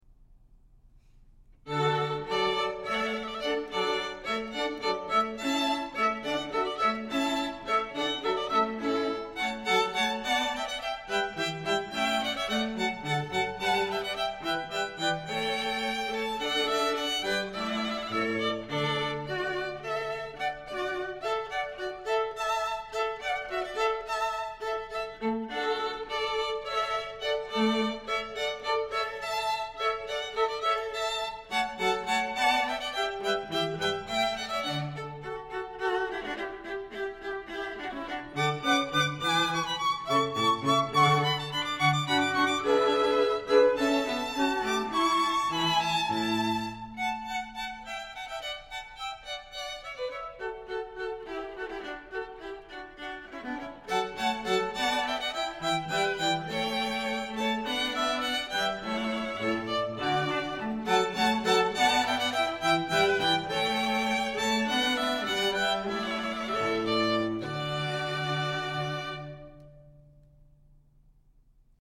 Elysium String Quartet – performing throughout the Lehigh Valley, Delaware Valley, and Philadelphia, PA